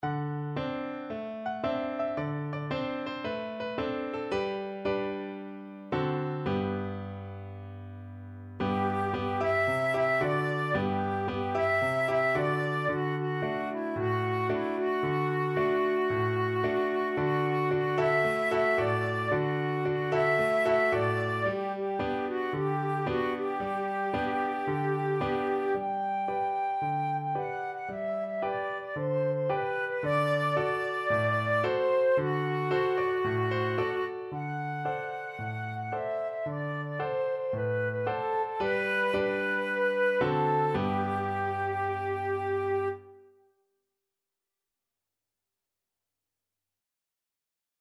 Traditional Trad. Oma Rapeti Flute version
Flute
Cheerfully! =c.112
4/4 (View more 4/4 Music)
G major (Sounding Pitch) (View more G major Music for Flute )